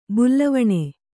♪ bullavaṇe